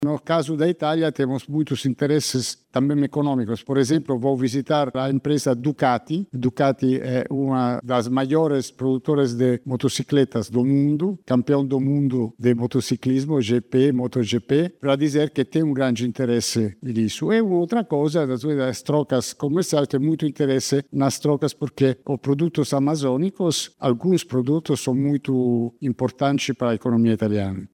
Durante o encontro, o chefe da missão diplomática italiana no Brasil destacou a importância de futuras parcerias entre o Amazonas e a Itália, como no campo econômico.